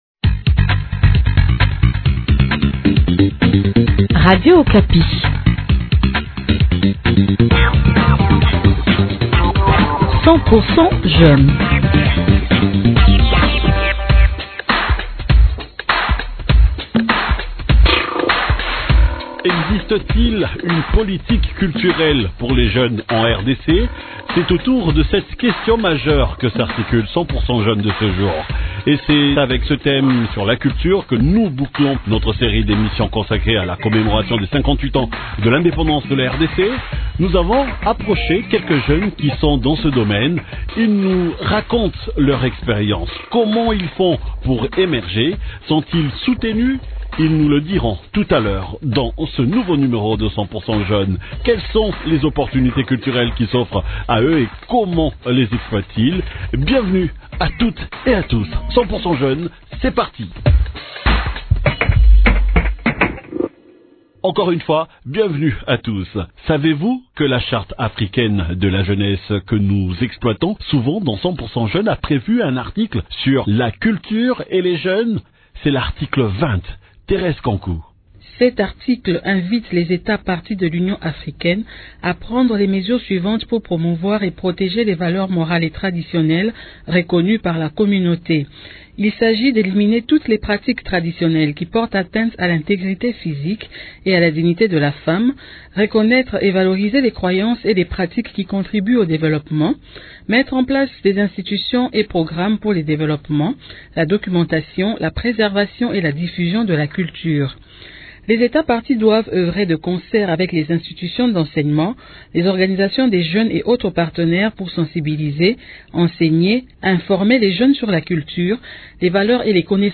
Ils nous racontent leur expérience.